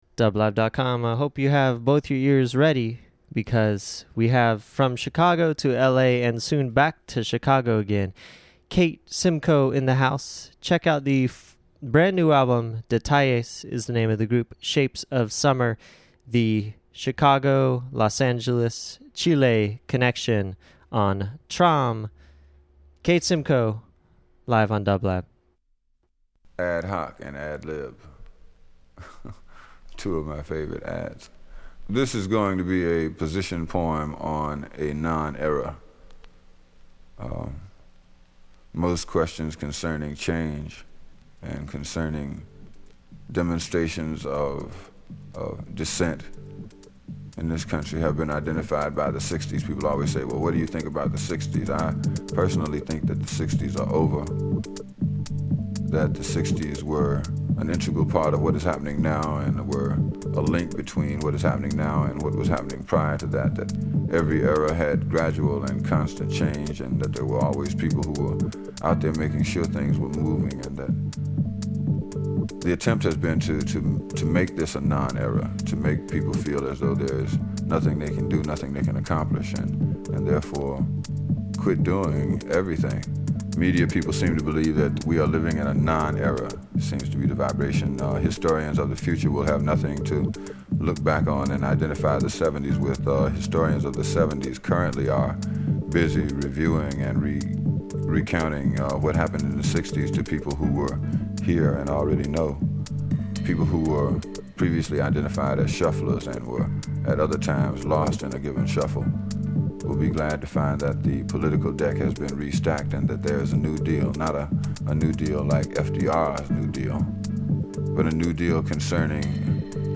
globe trotting mix.
house and downtempo sounds out of the US
jazz instrumental
saxophonist
Disco/House Electronic International